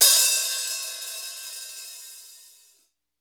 CYM X13 HA06.wav